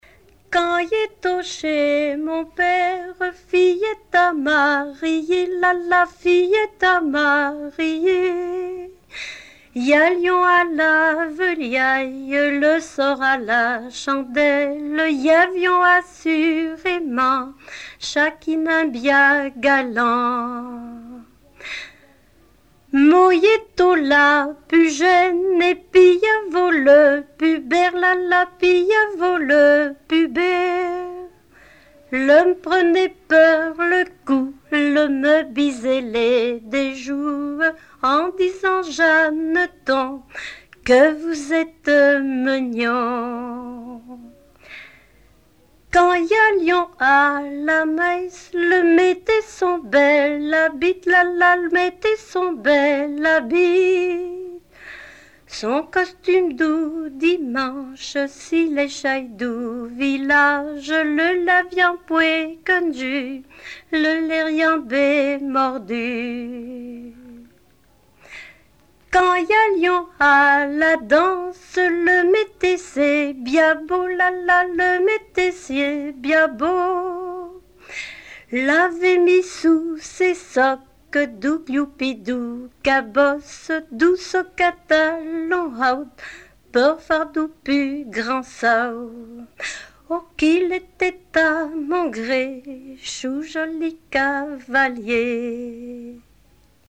Genre laisse
Chansons traditionnelles
Pièce musicale inédite